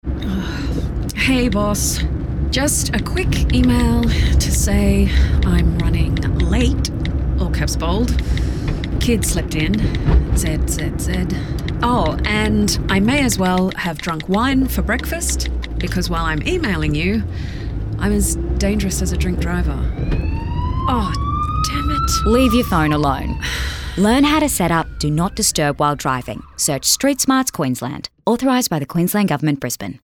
Distracted Driving Radio Campaign
With that in mind, the campaign was given a binaural mix allowing the listener a more immersive perspective of being ’in the car’ with the driver, hearing all of the surrounding audio triggers which assist in reinforcing the dangers of using your phone while driving.